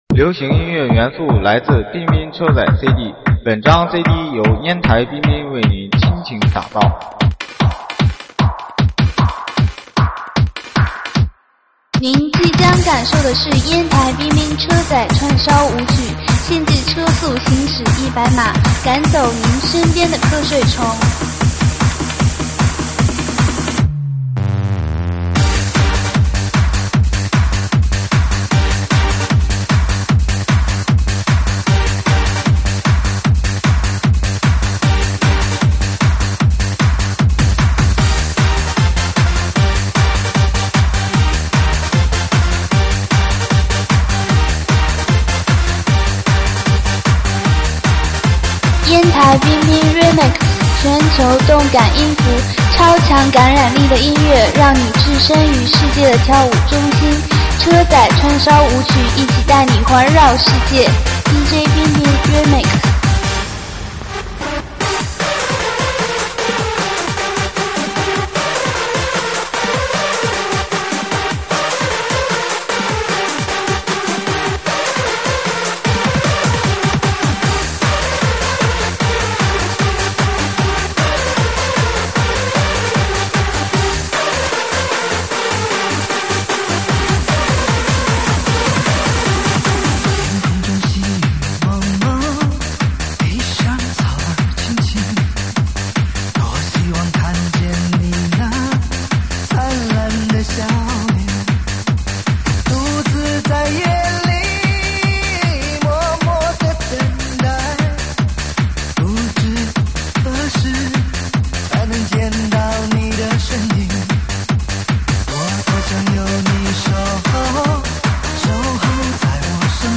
音质： 320 Kbps